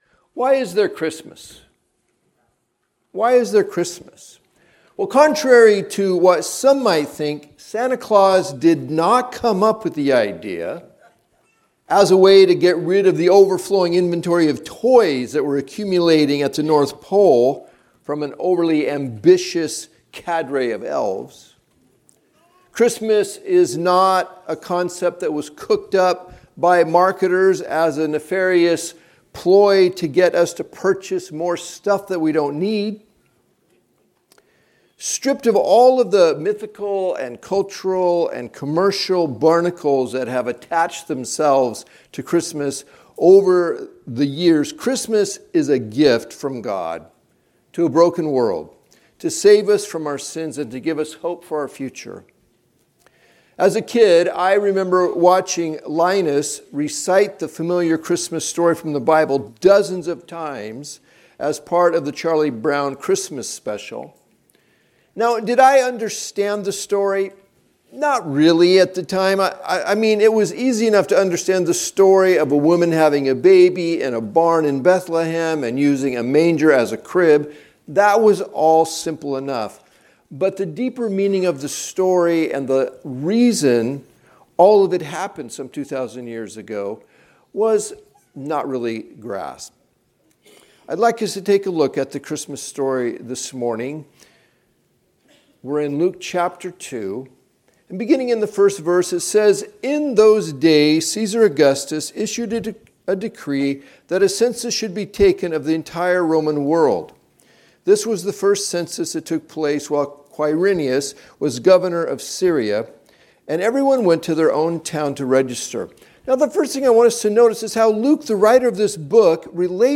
Recordings of the teaching from the Sunday morning worship service at Touchstone Christian Fellowship. Tune in each week as we move through the Bible in a way that is both relevant and challenging.